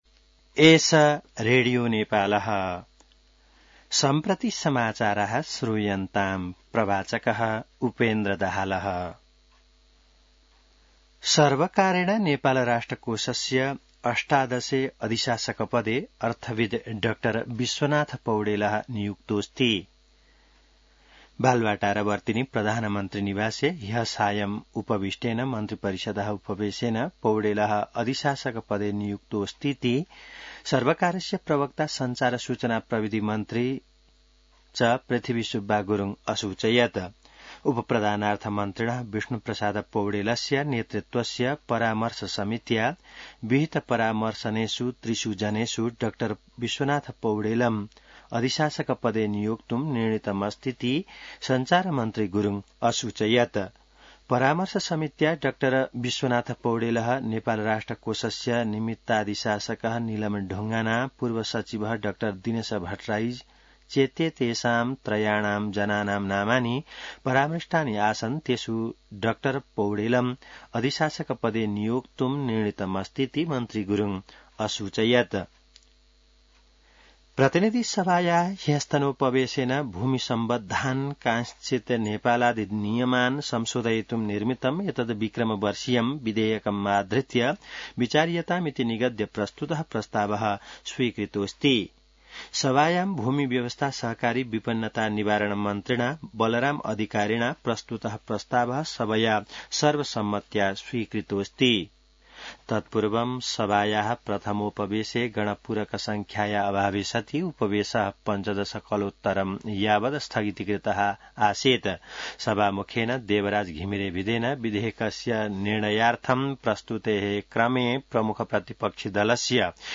संस्कृत समाचार : ७ जेठ , २०८२